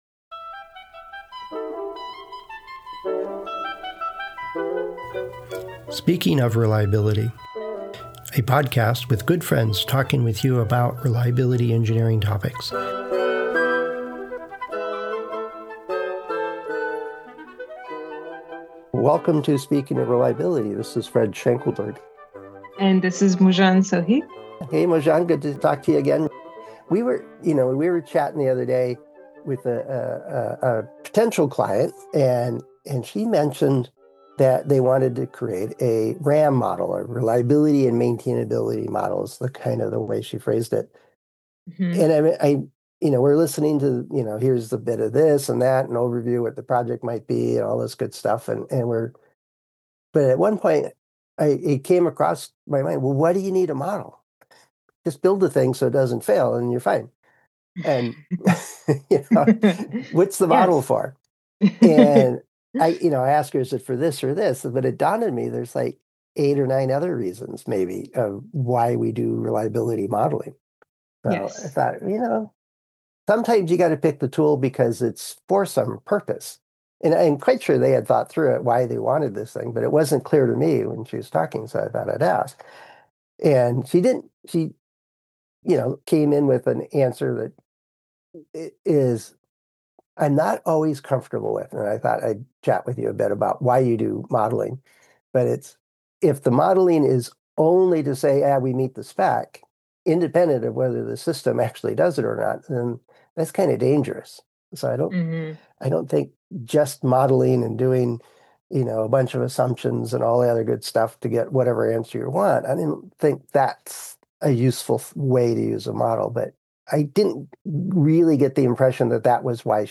Speaking Of Reliability: Friends Discussing Reliability Engineering Topics